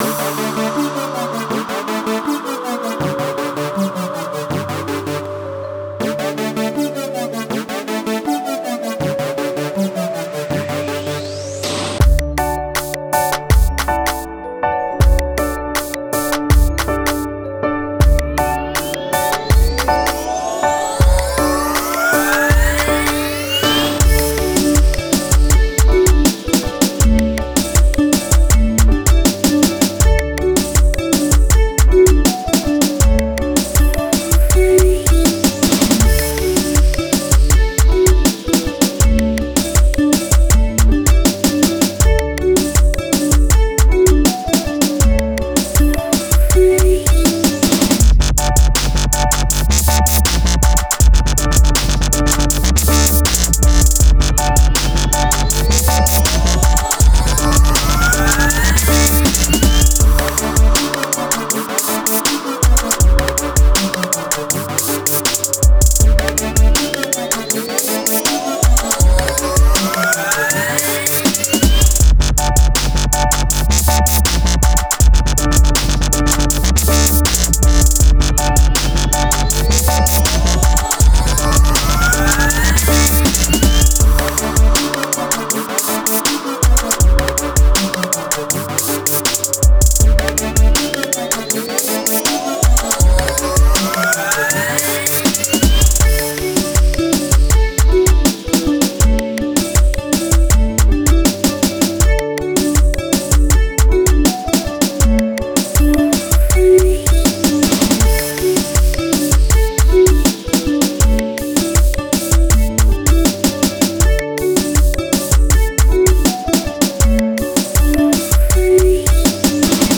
фишка была подсмотрена в раггатоне а именно в ударных (дэмбоу) по завершении лупа ударной партии всегда проходили ломанные снэйры вот это я и взял за основу! то есть конец партии в рагатоне в моем стиле был основным битом!
этого показалось мало позднее добавил в треки как особенность нейлоновую гитару(по анологии лидов в джифанке), делал треки год плюс я очень ленивый до хобби так что продвижением вообще не занимался так чисто в компании друзей-заценить!
ломаный бит 2.mp3